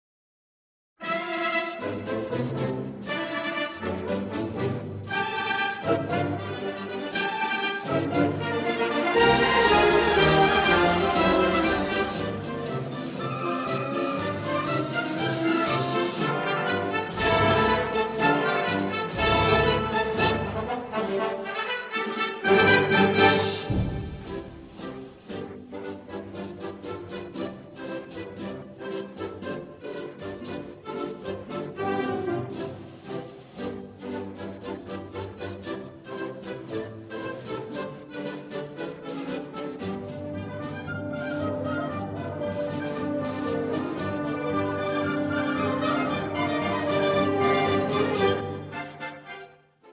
Suite sinfónica para Banda